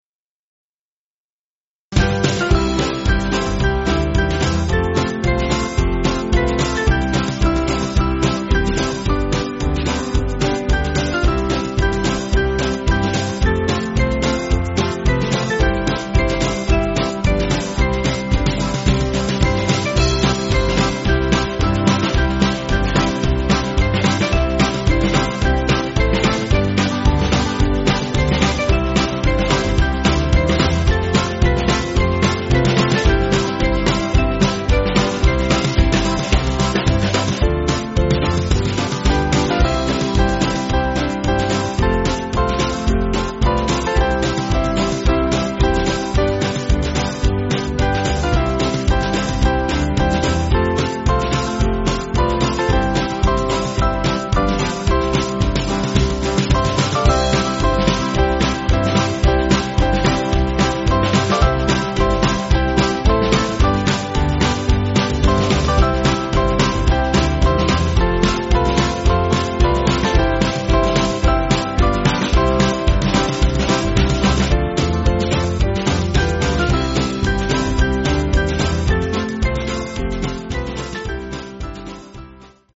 8.7.8.7.D
Small Band